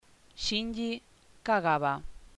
NOMBRE PRONUNCIACIÓN HABLANTE NATIVO HISPANOHABLANTE
Shinji KAGAWA Shínyi Kágaba